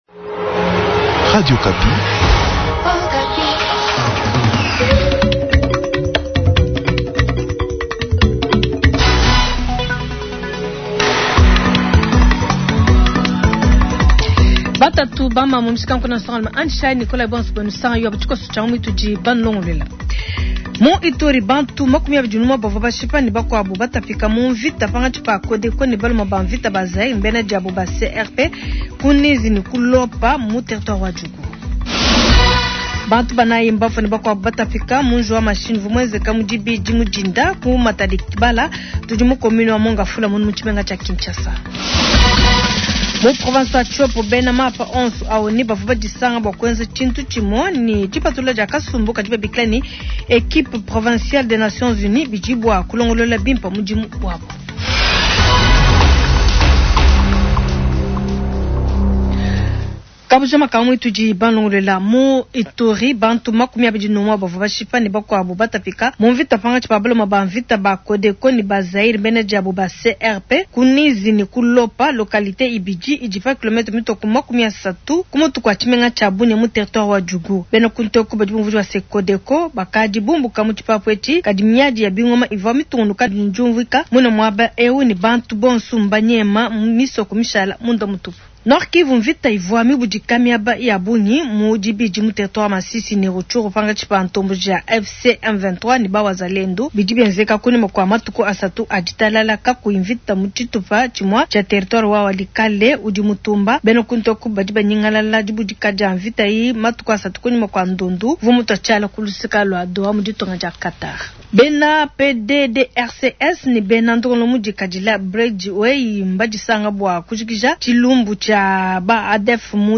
Journal soir